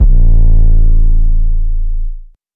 bigboi 808.wav